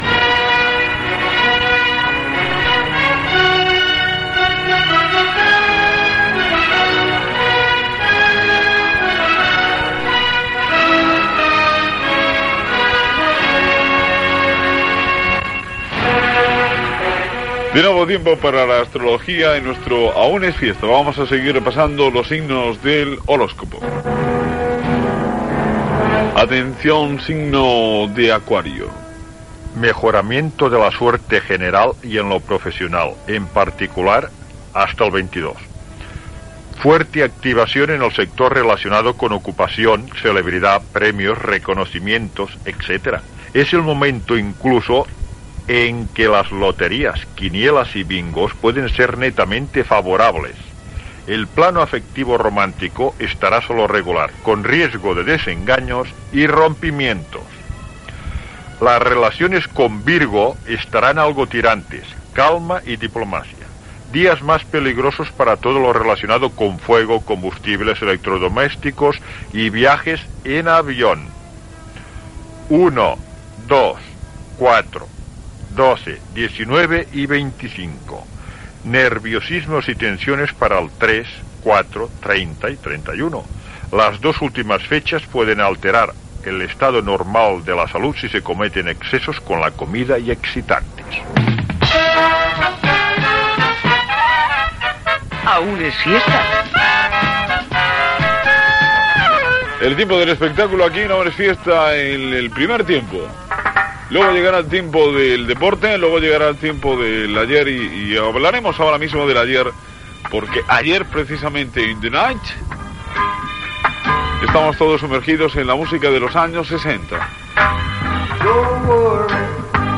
Entreteniment
Magazín dels diumenges a la tarda i el capvespre.